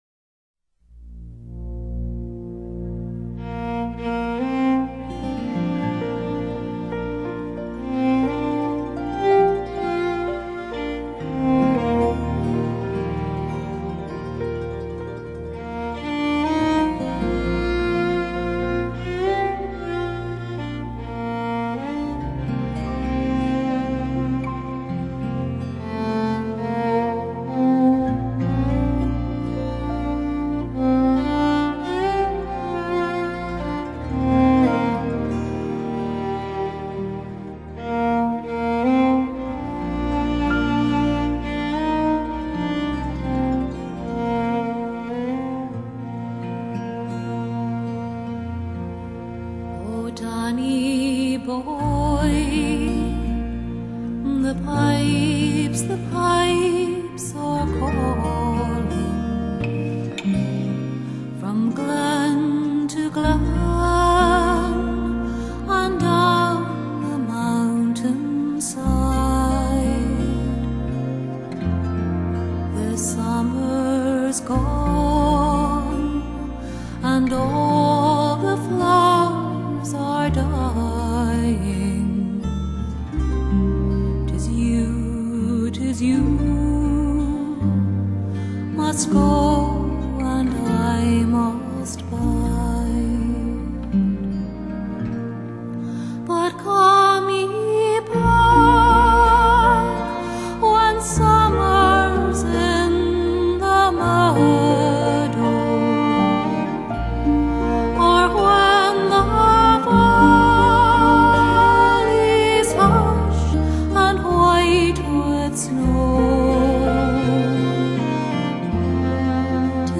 专辑分类：传统音乐 凯尔特音乐